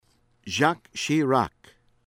CHEVENEMENT, JEAN-PIERRE ZAHN(n)   pee-AIR   SHEH-vehn-moh